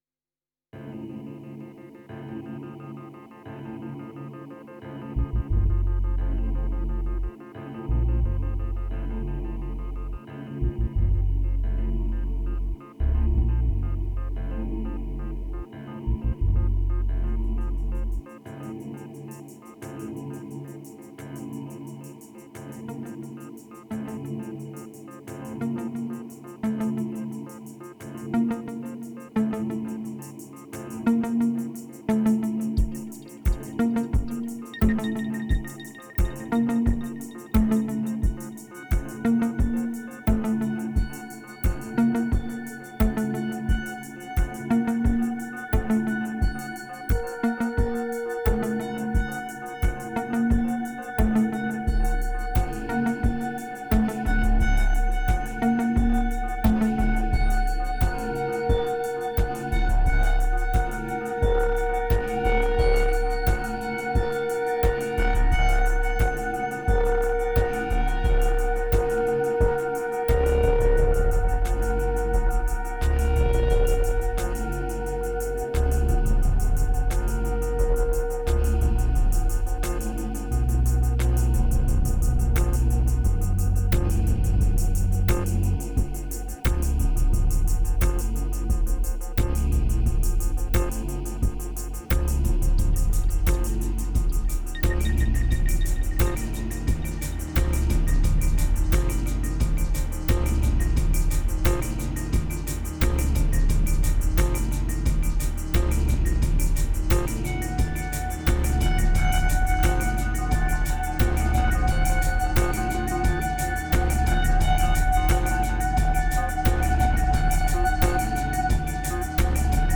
2423📈 - 31%🤔 - 88BPM🔊 - 2017-06-03📅 - -80🌟